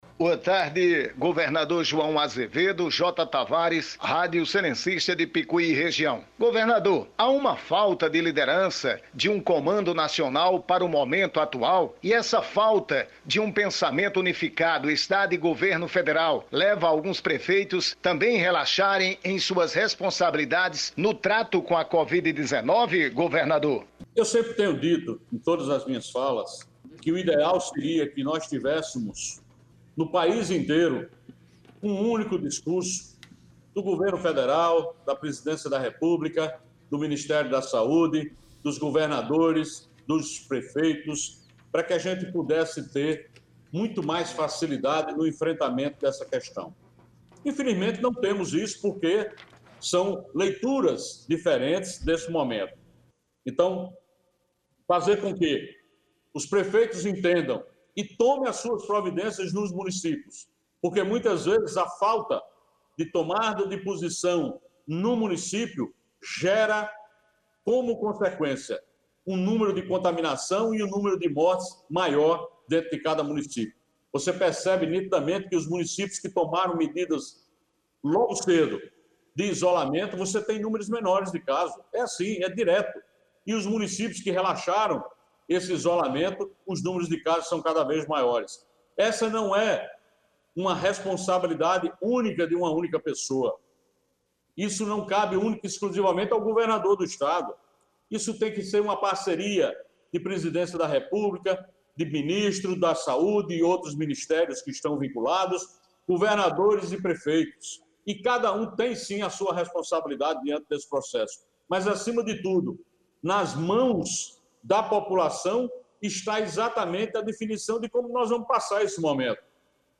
fala-gov.mp3